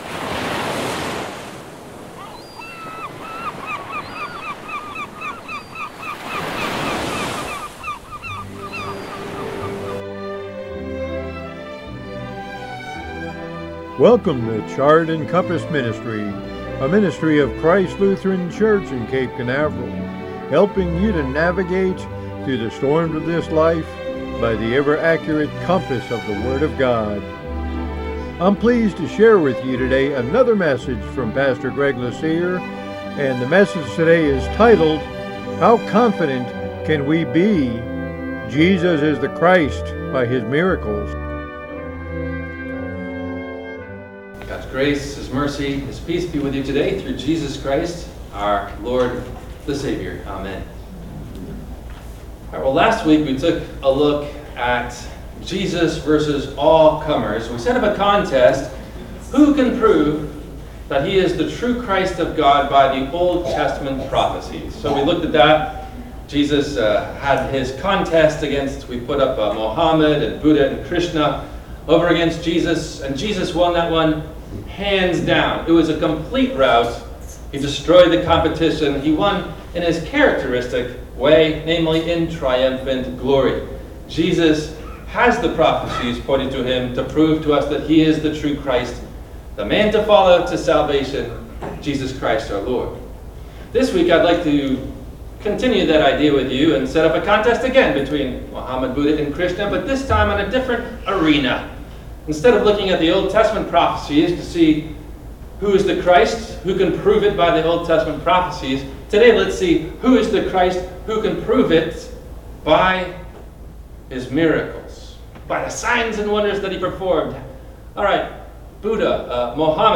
How Confidant Can We Be Jesus Is The Christ By His Miracles? – WMIE Radio Sermon – December 23 2024